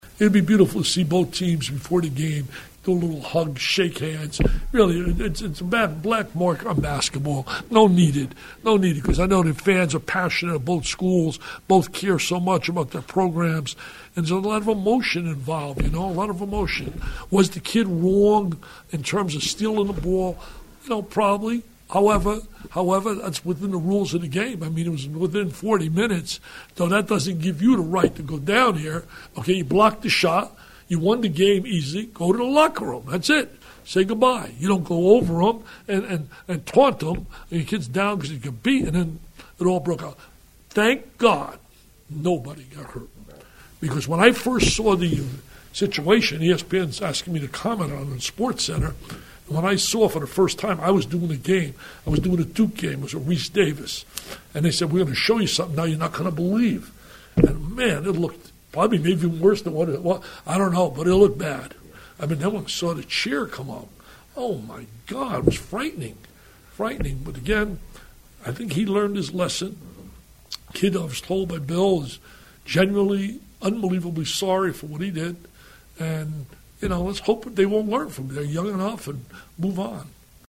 Vitale met with the media prior to the banquet.